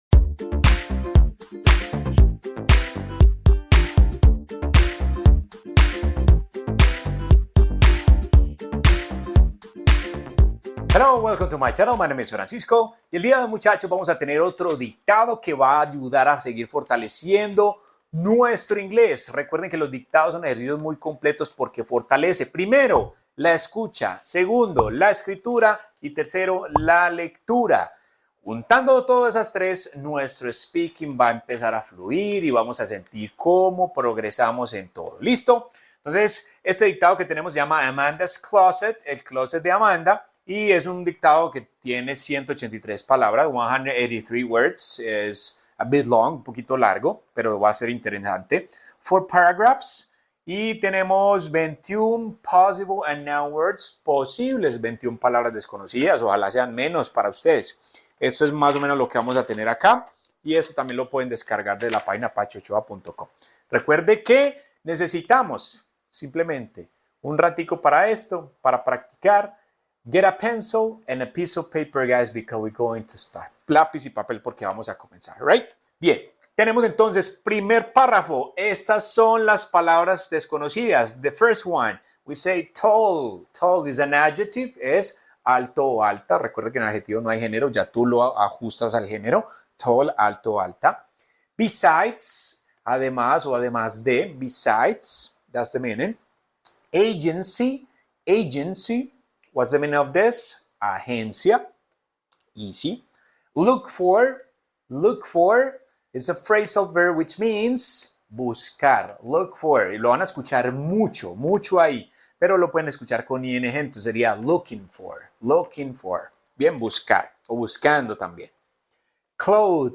Dictado en inglés que entrena tu oído, lectura y escritura con precisión total